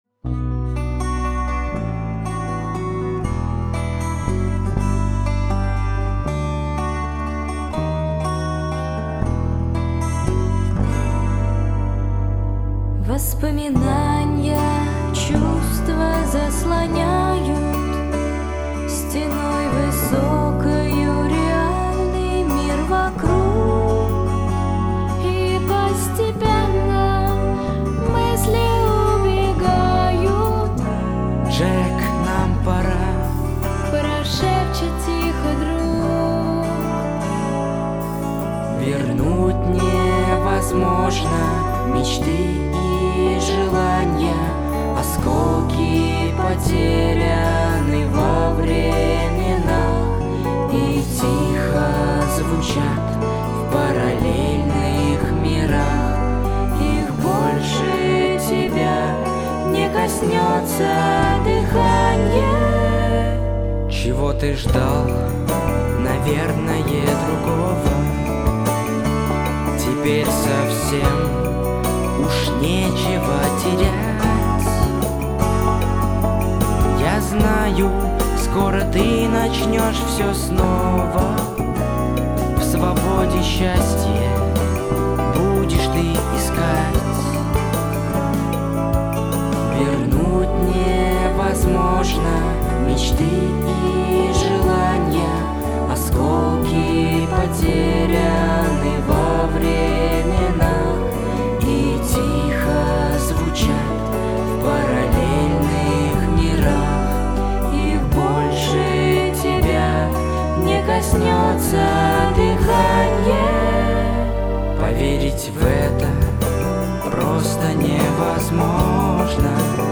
Вокальная группа
Соло-гитара, ритм-гитара, бас-гитара, синтезатор
Ударные
Записано в студии